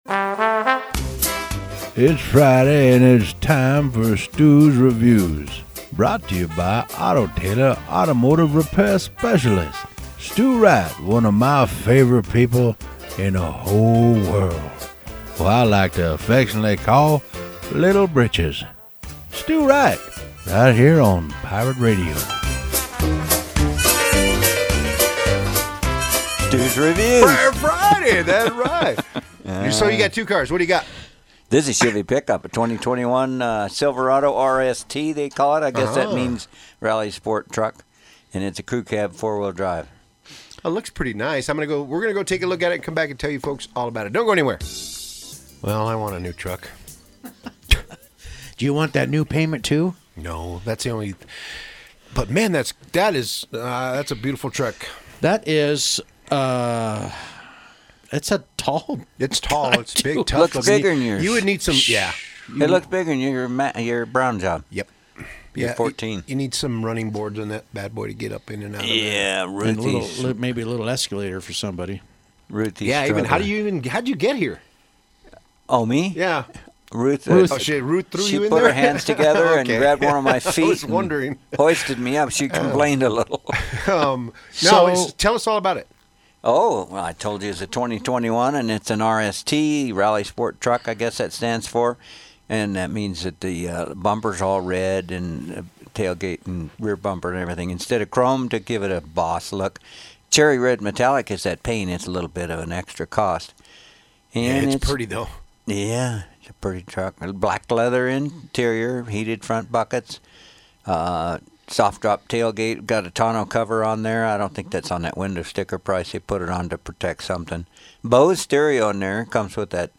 Radio Review at Pirate 104.7 studios